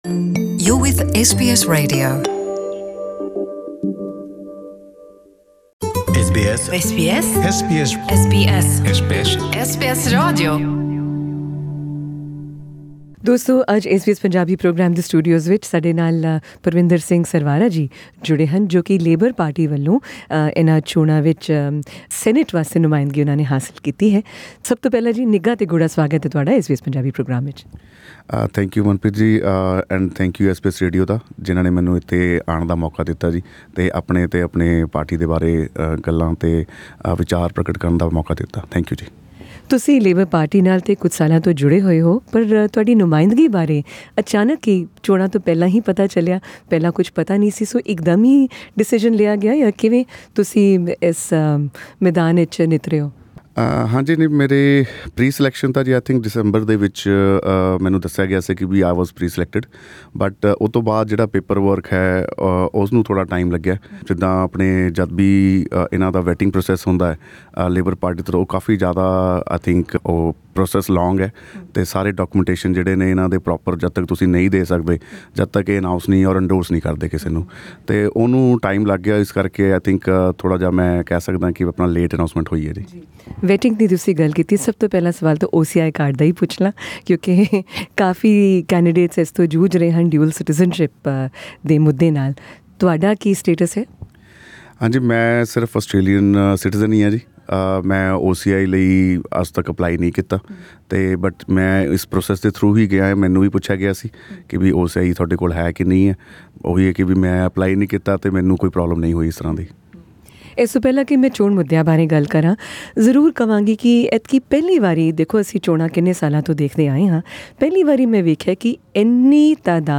He answered questions about his candidacy as well about allegations made about his construction business, in an interview with SBS Punjabi.